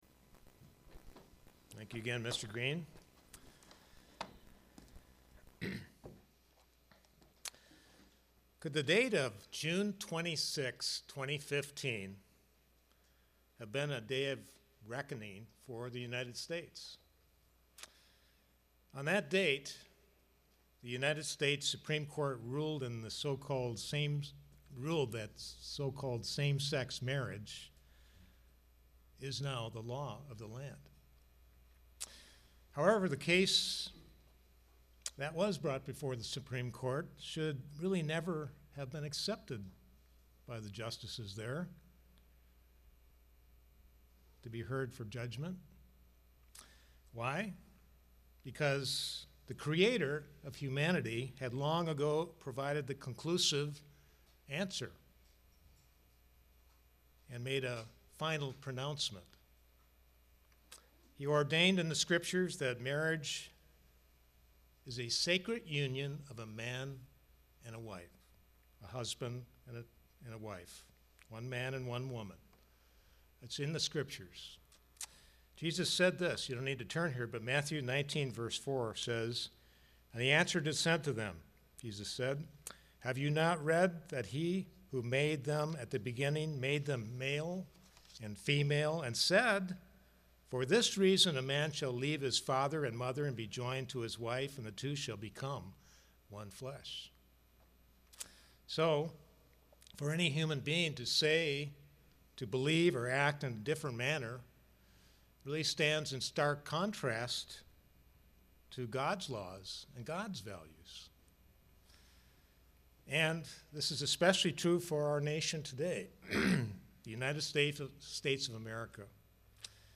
Sermons
Given in Kingsport, TN